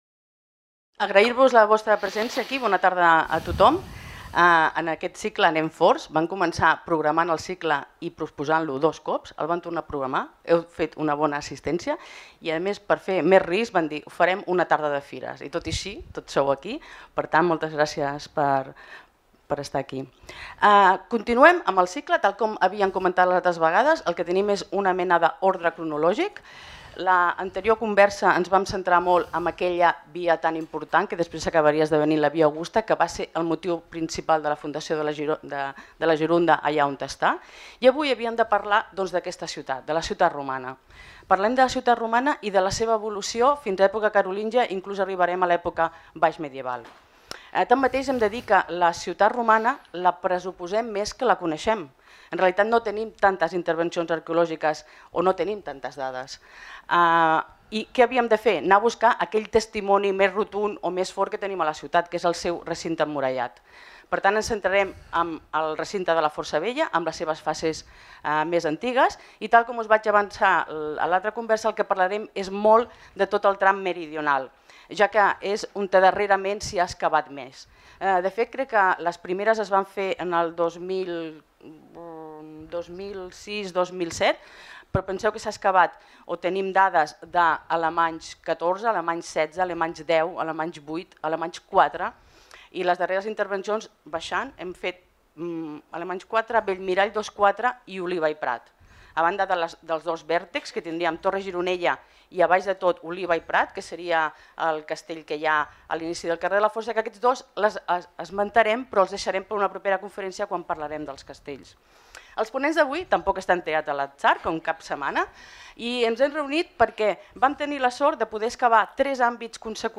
Aquesta conferència, celebrada en el marc del Cicle de conferències de l'Institut d'Estudis Gironins, tracta l'evolució històrica de la ciutat de Girona, centrant-se especialment en el recinte de la Força Vella, des de l'època romana fins a la carolíngia. La discussió se centra particularment en el tram meridional del recinte, on s'han dut a terme les excavacions més exhaustives.